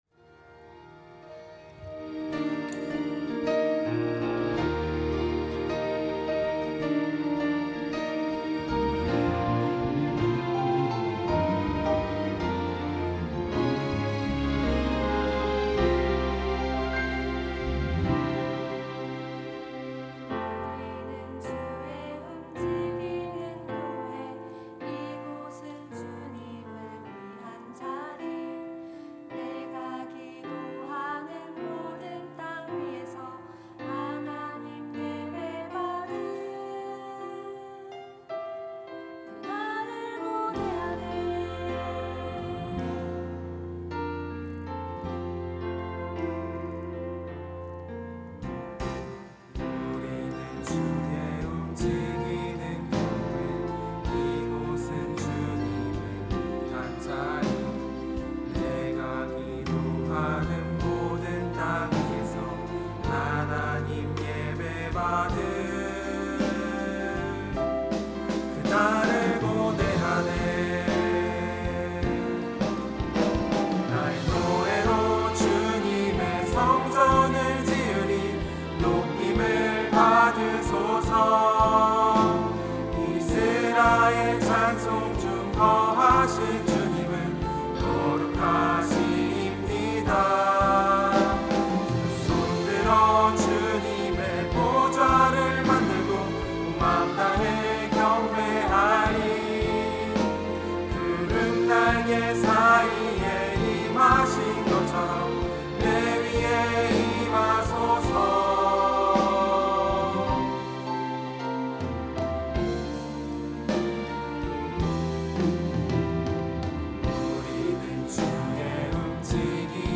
청년부 2022년도 2팀 9셀